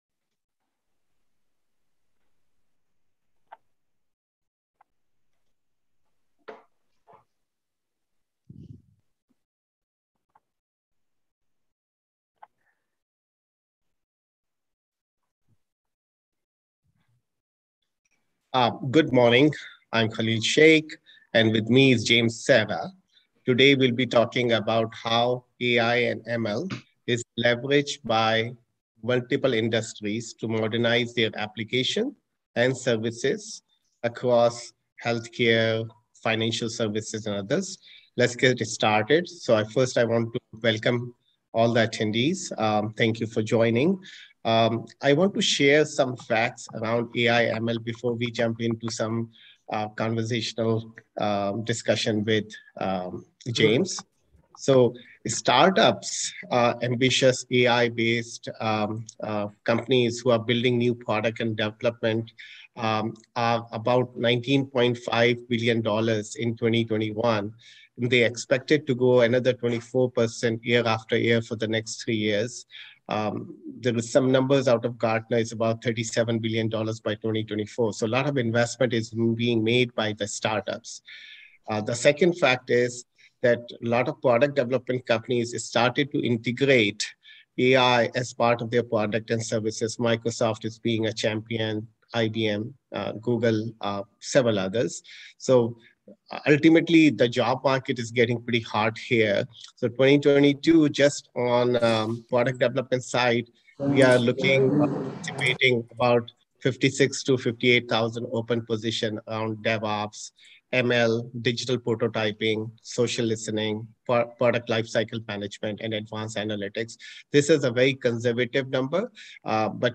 A podcast series where data and analytics leaders discuss enterprise AI, data modernization, and digital transformation strategies.